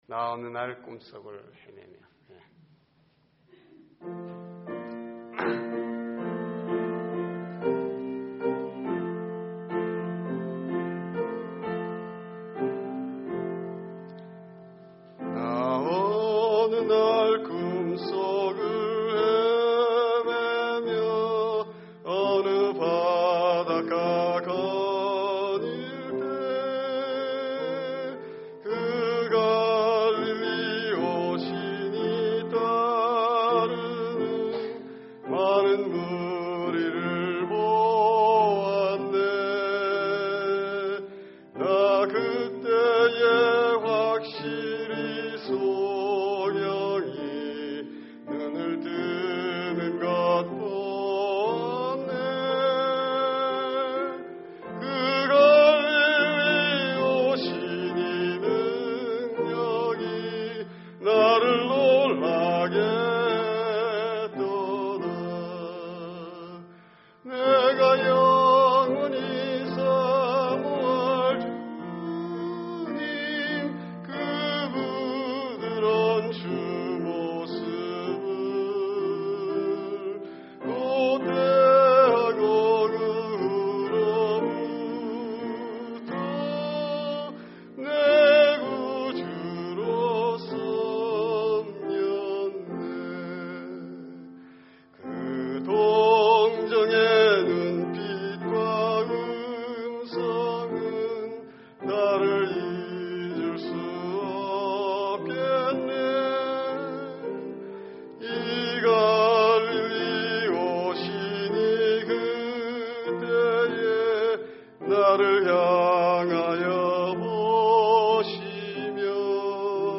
Special Music